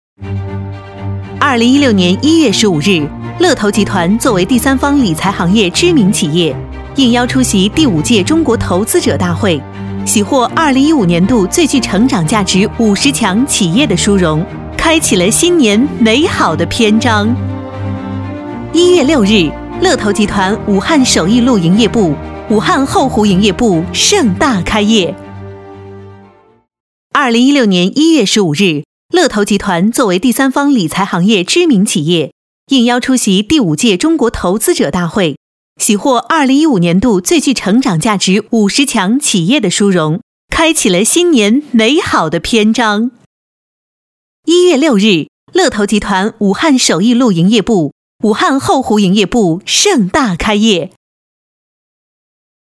国语青年沉稳 、积极向上 、亲切甜美 、女课件PPT 、工程介绍 、旅游导览 、宣传片 、80元/分钟女S143 国语 女声 语音播报-世界第一梯-宣传片-甜美 沉稳|积极向上|亲切甜美